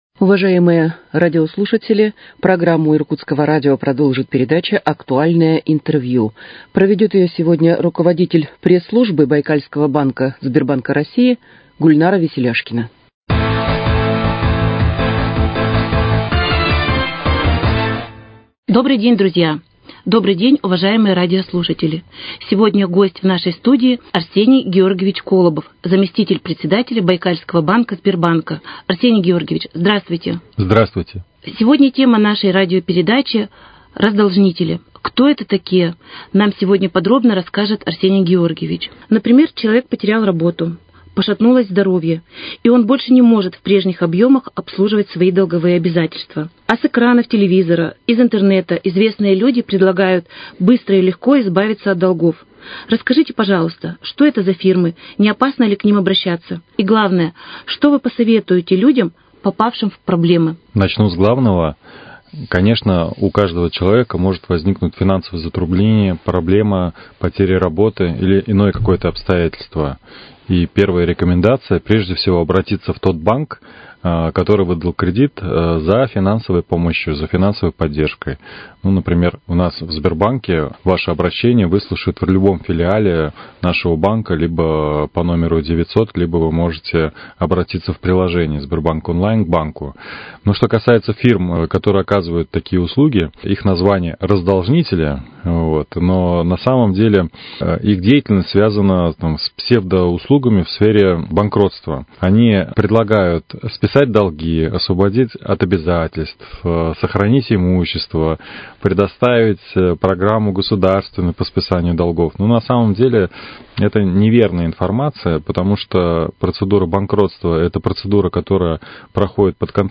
Актуальное интервью: Раздолжнители. Кто может помочь справиться с долгами?